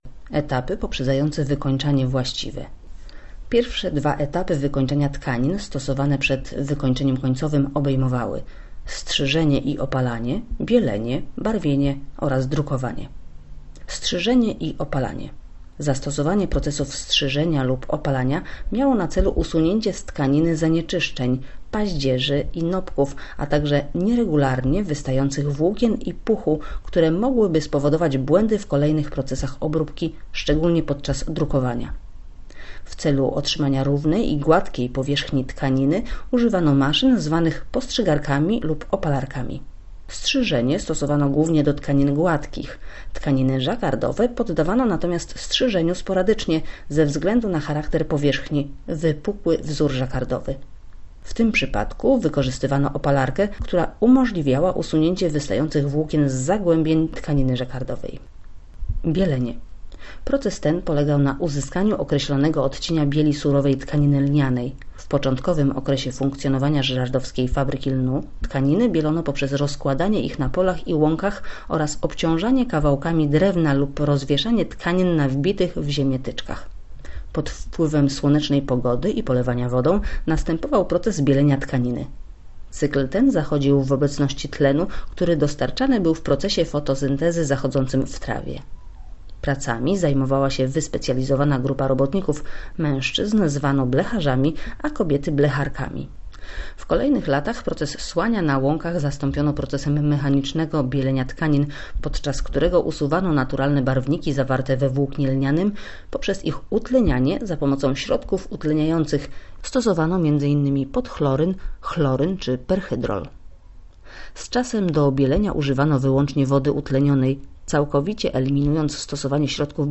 Audioprzewodnik po Muzeum Lniarstwa już dostępny